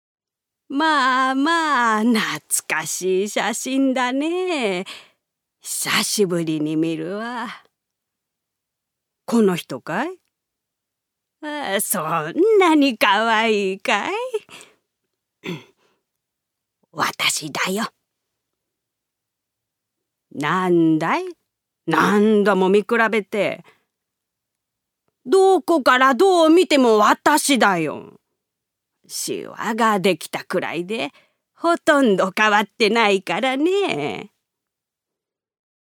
女性タレント
音声サンプル
セリフ４